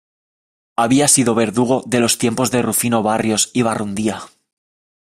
Pronunciato come (IPA)
/beɾˈduɡo/